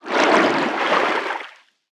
Sfx_creature_featherfish_swim_slow_01.ogg